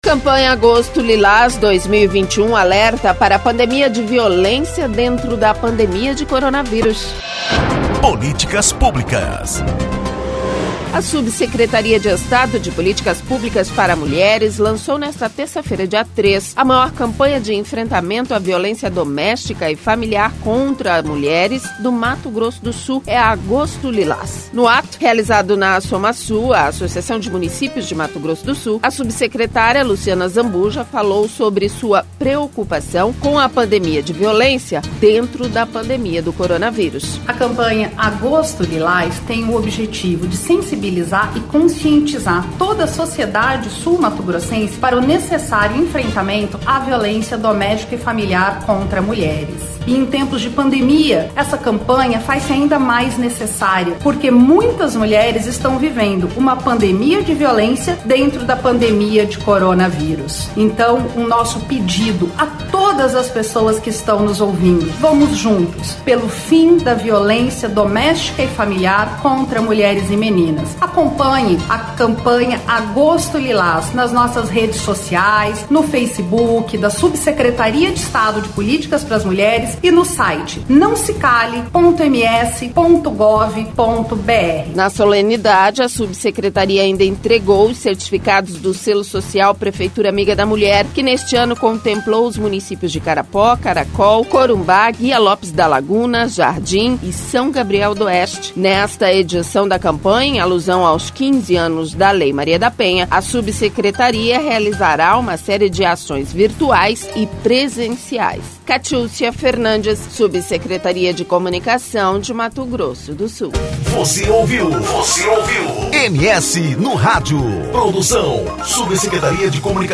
No ato, realizado na Assomasul, a subsecretária Luciana Azambuja, falou sobre sua preocupação com a pandemia de violência dentro da pandemia do coronavírus.